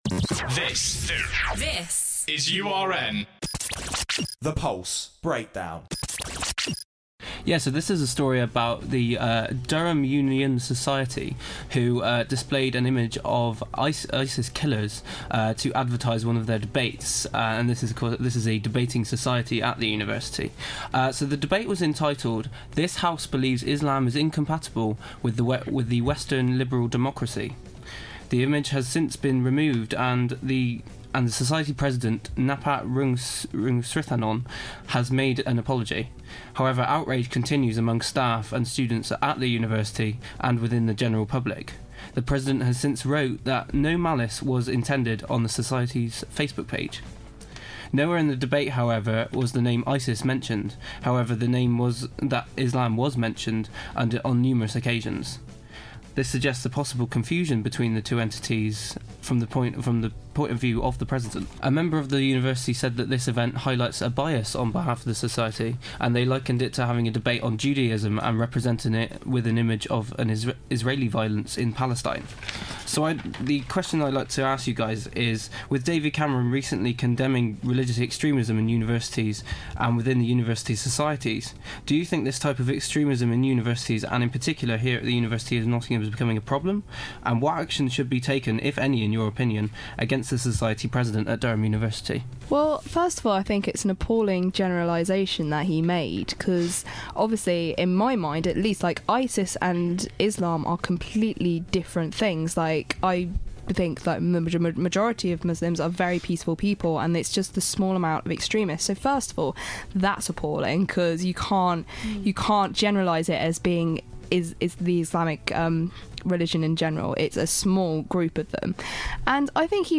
The Pulse team discuss Durham Universities controversial advertising decision.